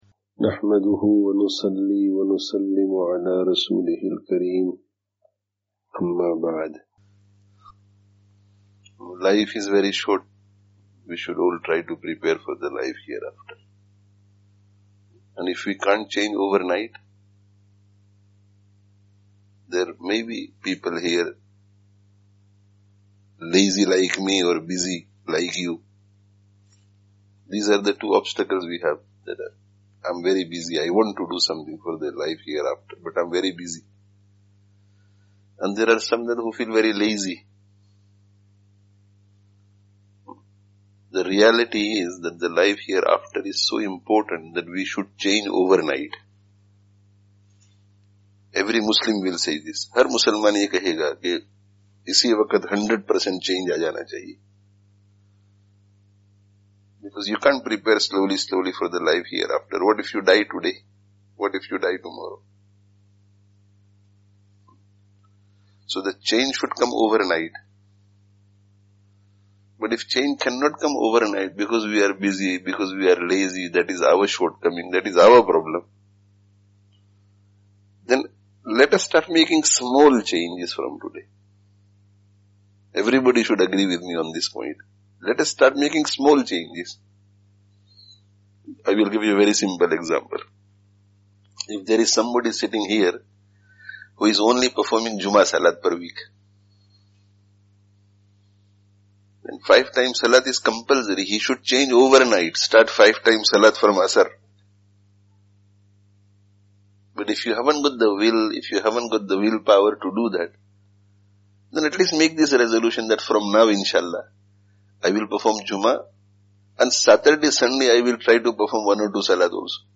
Nikāh Bayan (19/09/20)